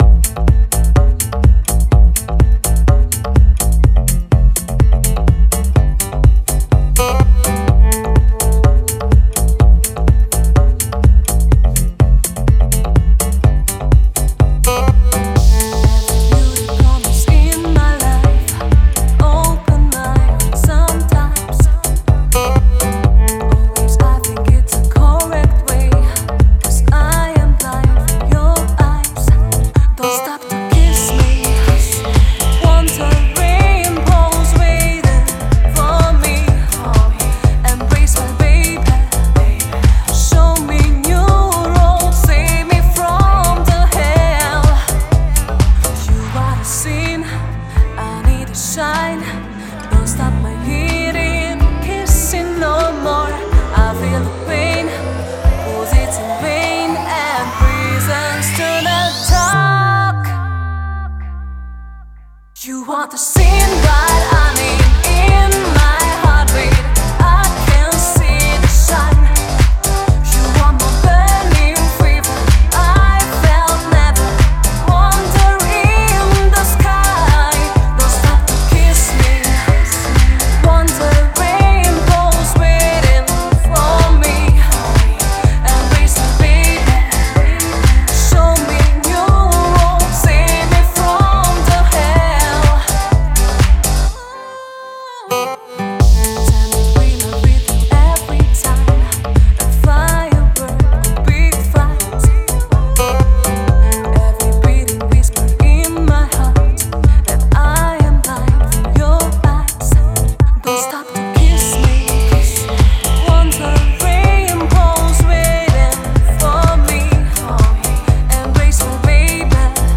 deep remix